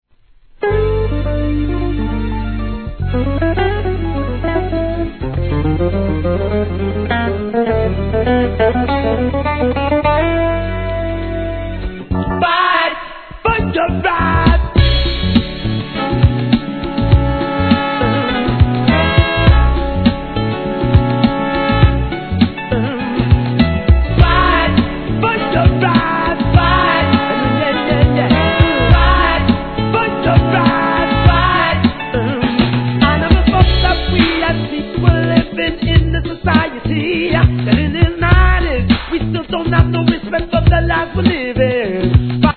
HIP HOP/R&B
激オシャレ＆FUNKYなトラックでの代表作!!